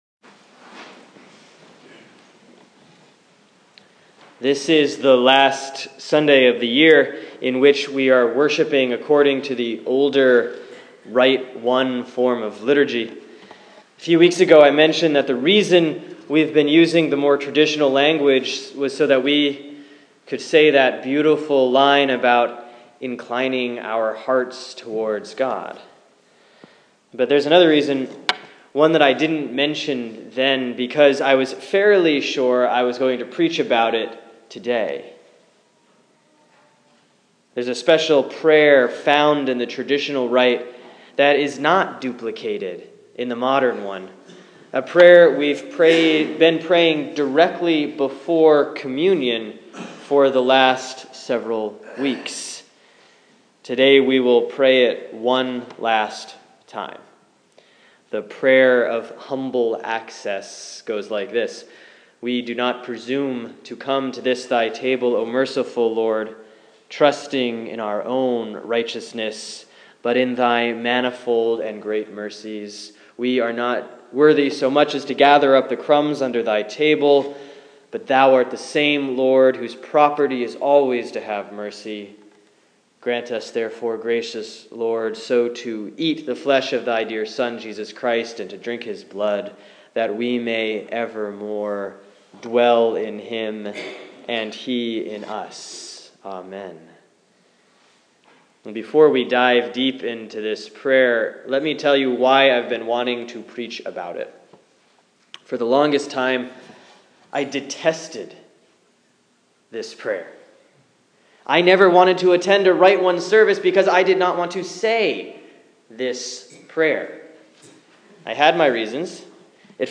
Sermon for Sunday, March 22, 2015 || Lent 5B || Jeremiah 31:31-34; Psalm 51:1-13